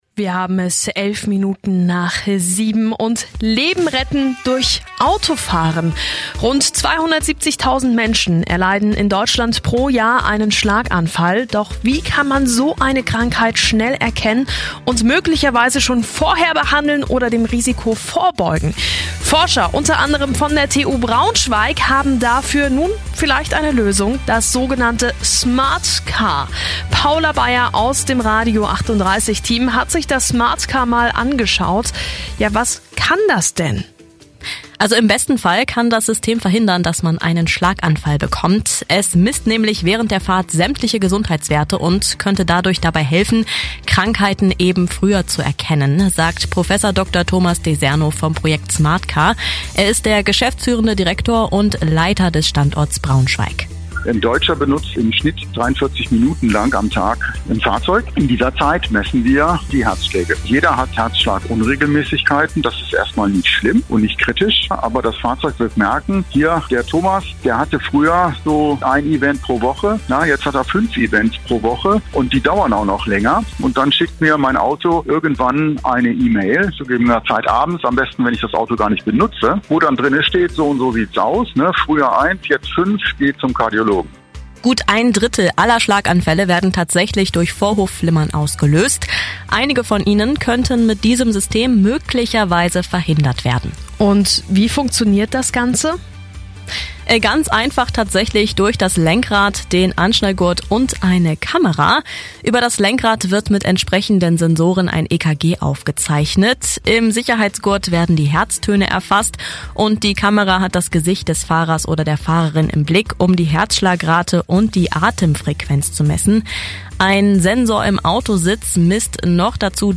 Bereits im November 2024 wurde das Smart Car Forschungsprojekt von Radio 38 interviewt.